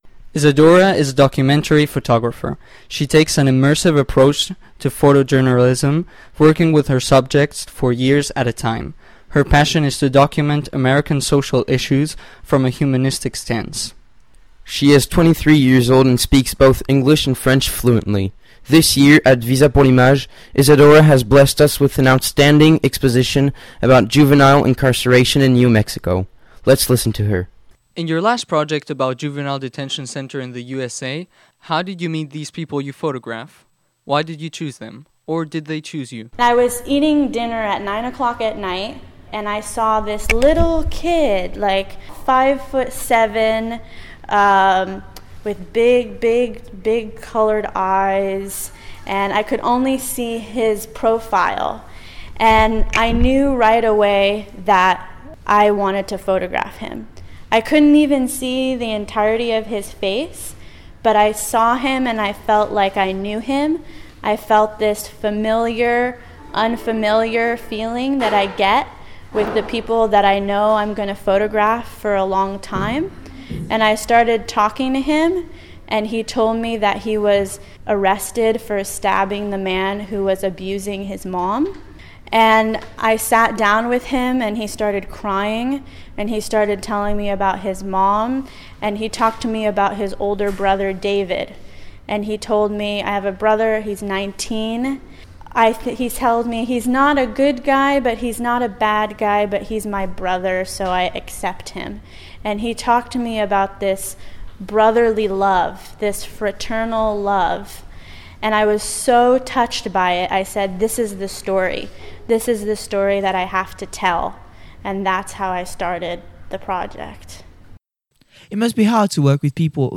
Interview en anglais.